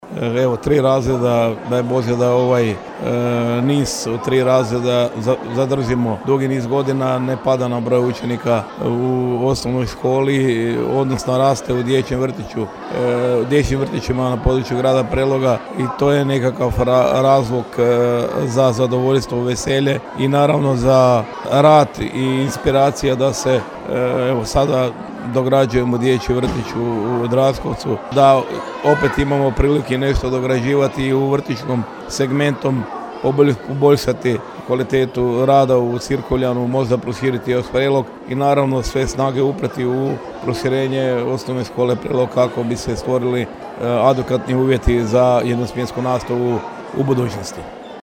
Prvi dan školske godine počeo je uzbudljivo, posjetima SŠ Prelog, OŠ Draškovec te OŠ Prelog poručio je gradonačelnik, Ljubomir Kolarek: